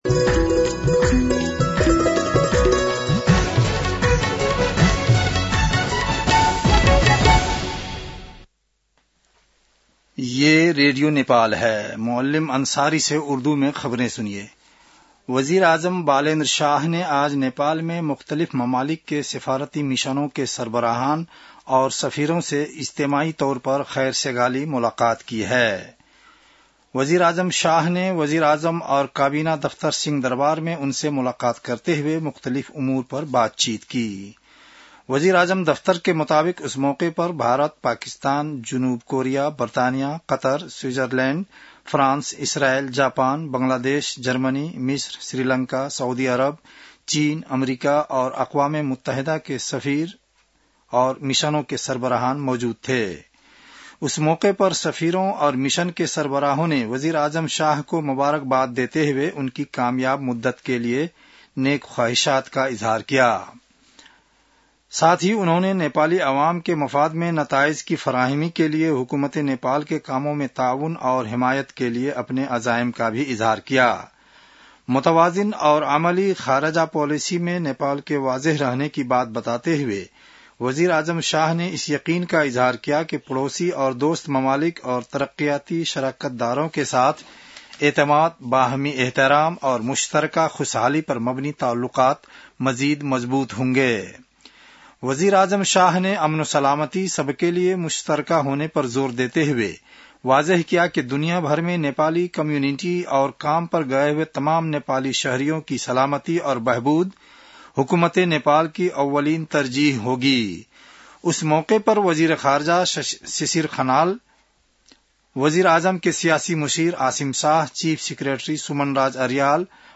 उर्दु भाषामा समाचार : २५ चैत , २०८२